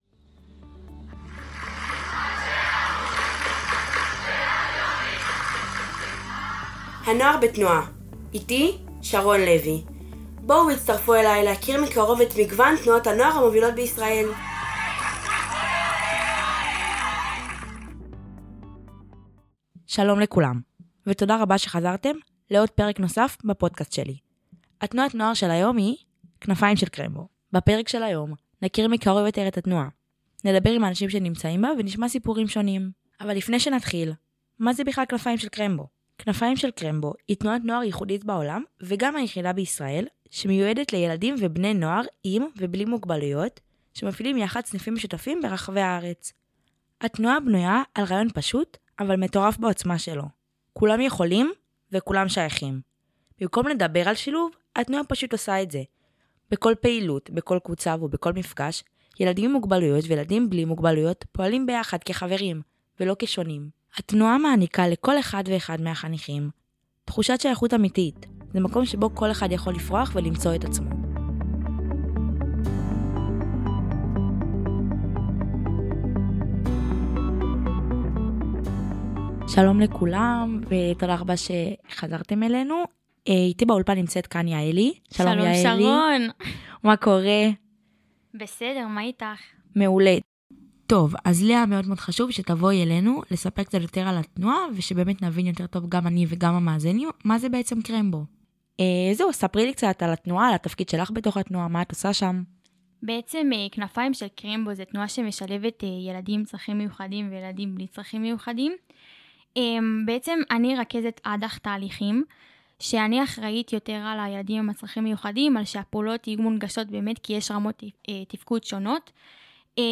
מרואיינת